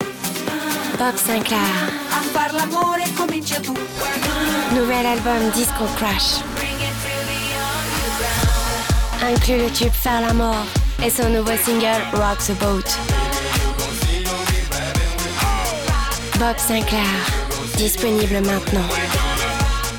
BOB SINCLAR – PUB RADIO – VOIX GLAM